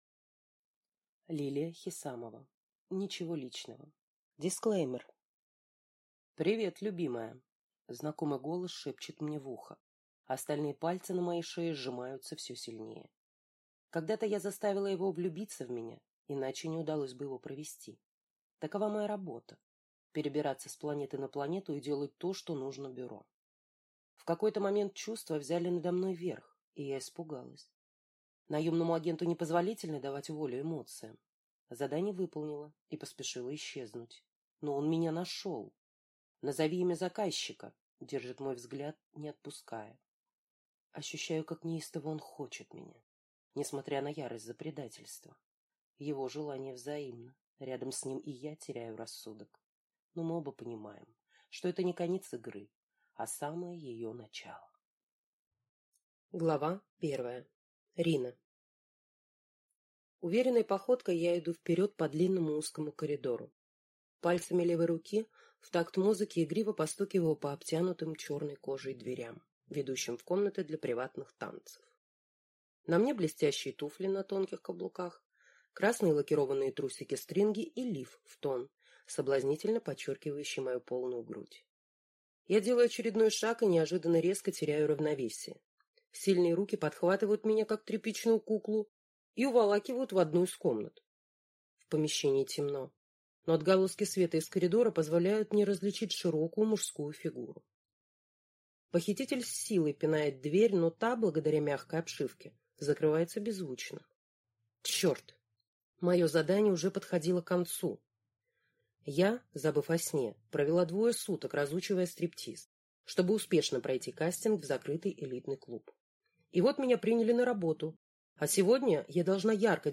Аудиокнига Ничего личного | Библиотека аудиокниг